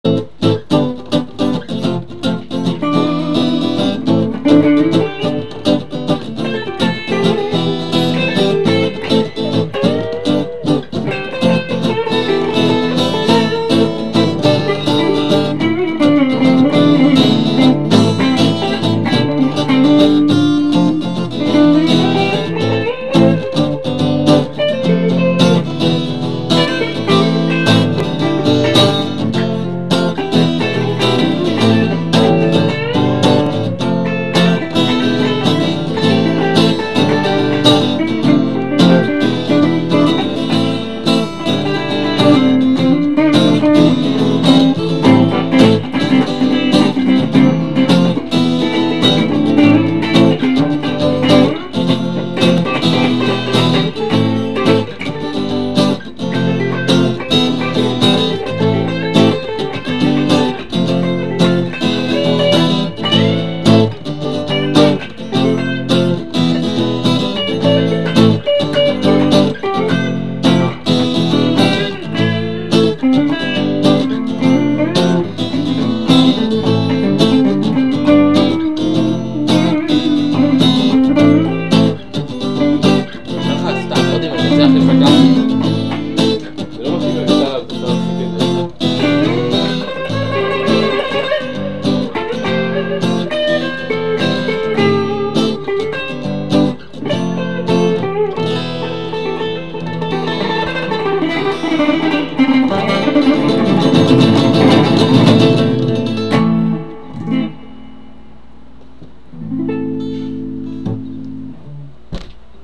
גיטרות
אני מלווה בגיטרה אקוסטית והוא עושה את הסולו בחשמלית.
הסיום ממש מוצלח, לעומת שאר הזמן שהליווי חוזר על עצמו, אבל זה בסדר גמור בגלל שהסולו מוצלח מספיק כדי שלא לשים לב יותר מדי לליווי.
האקורדים למען האמת די פשוטים:
הסולו זה סתם אילתור שלו.
שילוב הצלילים של האקוסטית עם החשמלית יושב טוב,